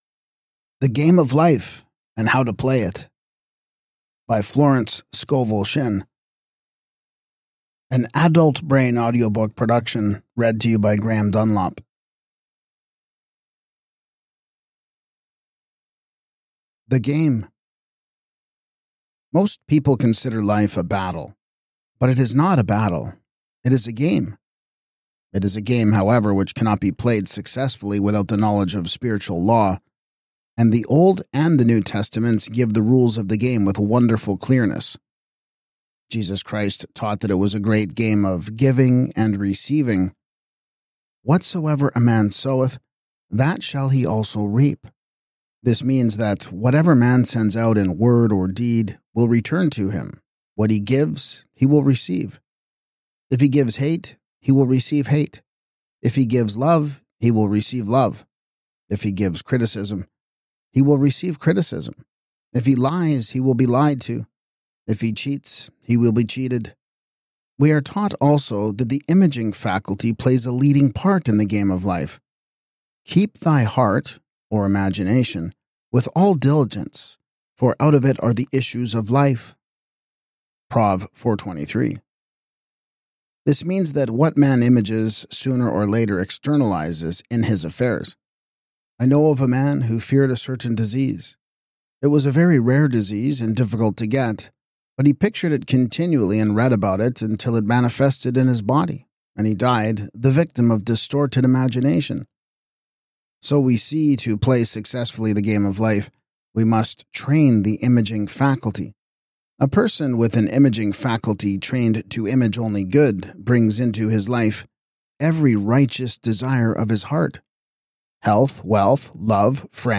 🔹 Key Lessons from This Audiobook: